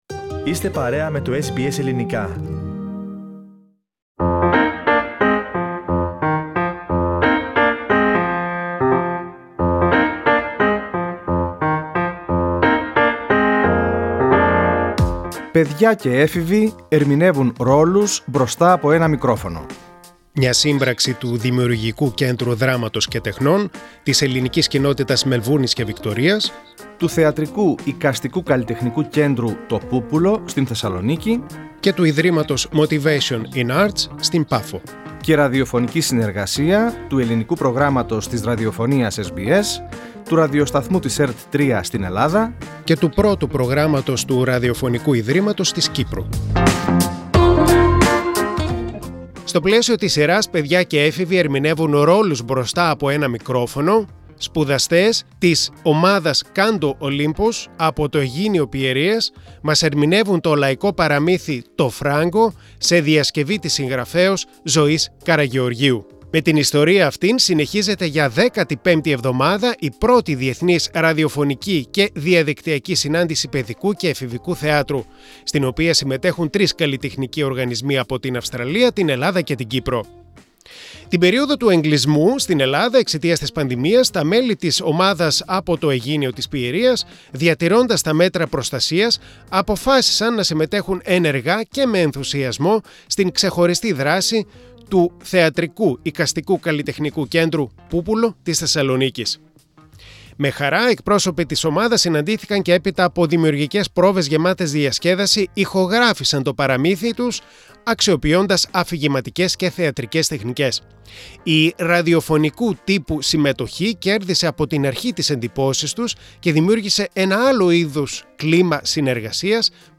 Σπουδαστές της θεατρικής ομάδας «Canto Olympus».
Με χαρά, εκπρόσωποι της ομάδας συναντήθηκαν και, έπειτα από δημιουργικές πρόβες γεμάτες διασκέδαση, ηχογράφησαν το παραμύθι αξιοποιώντας αφηγηματικές και θεατρικές τεχνικές.